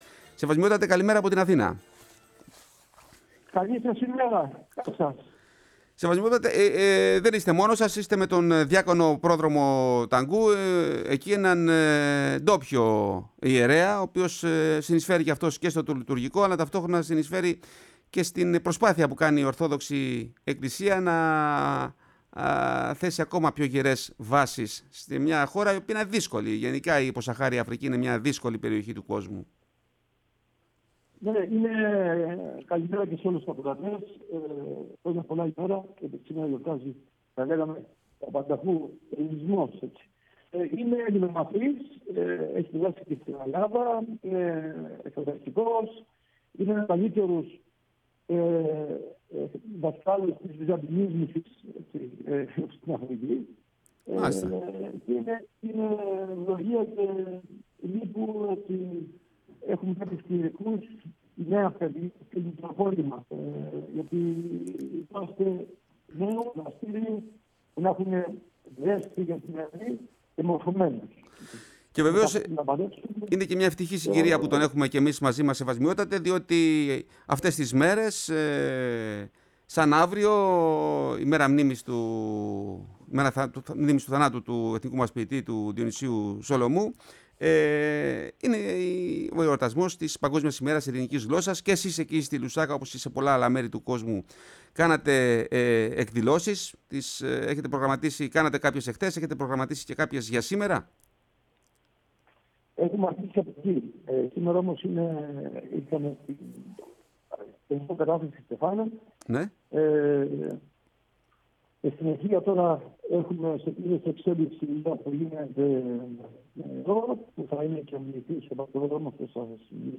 μιλώντας στη «Φωνή της Ελλάδας» και στην εκπομπή «Η Ελλάδα στον κόσμο»